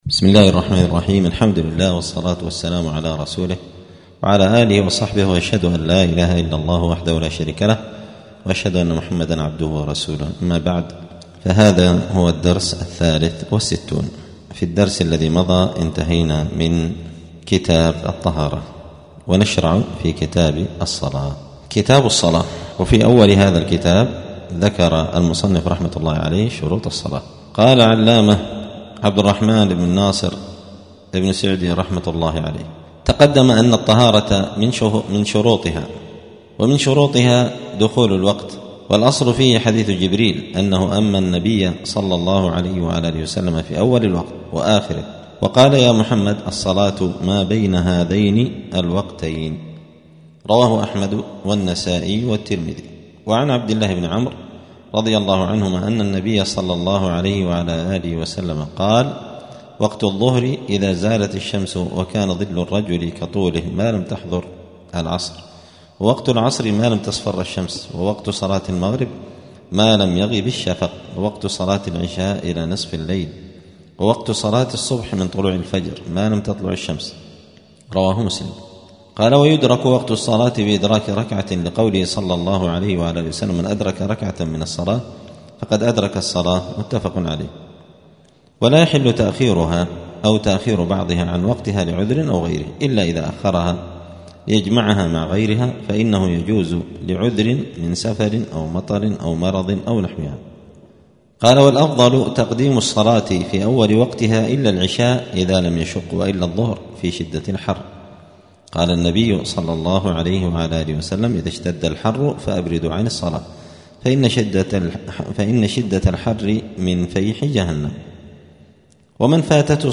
*الدرس الثالث والستون (63) {كتاب الصلاة باب شروط الصلاة تعريف}*
دار الحديث السلفية بمسجد الفرقان قشن المهرة اليمن